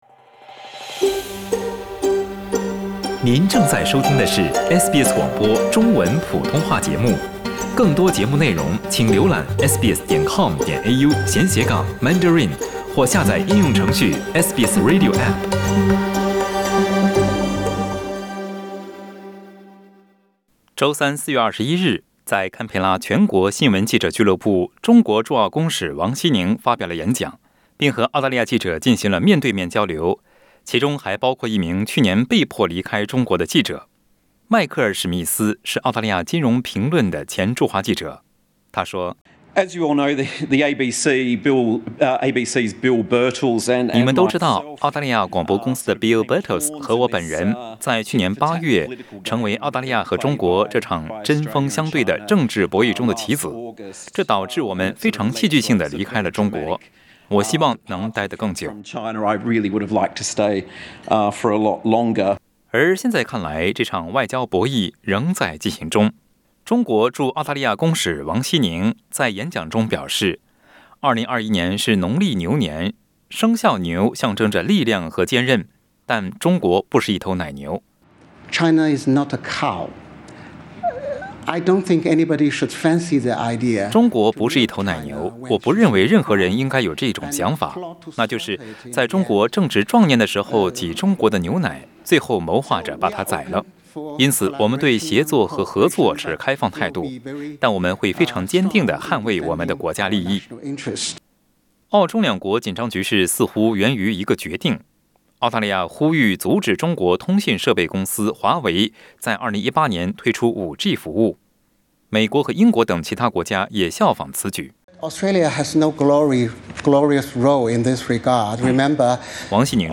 中国驻澳大利亚公使王晰宁警告说，中国不会容忍被人利用。他在全国新闻记者俱乐部对媒体的一次讲话中指责澳大利亚挑起了一场持续了三年多的外交争端。